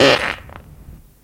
文件夹里的屁 " 屁41
描述：从freesound上下载CC0，切片，重采样到44khZ，16位，单声道，文件中没有大块信息。
Tag: 喜剧 放屁 效果 SFX soundfx 声音